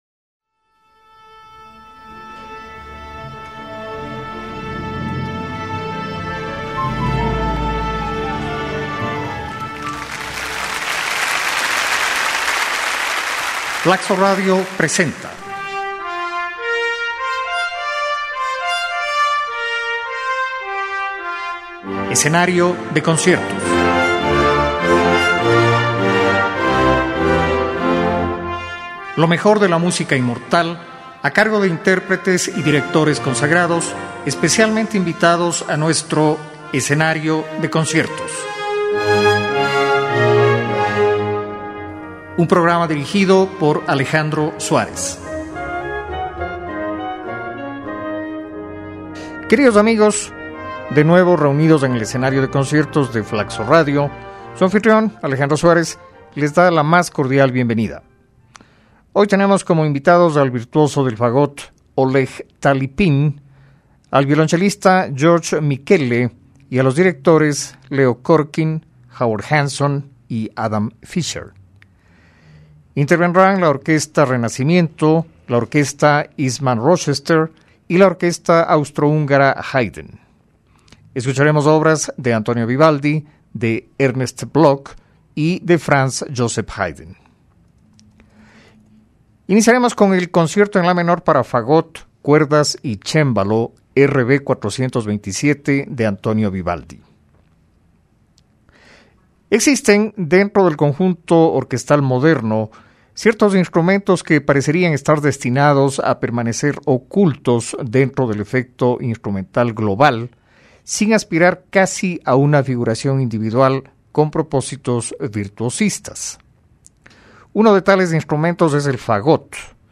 CONCIERTO EN LA MENOR PARA FAGOT, CUERDAS Y CEMBALO
fagot
PARA VIOLONCELO Y ORQUESTA
violoncelo
Director: Howard Hanson
SINFONIA N. 56 EN DO MAYOR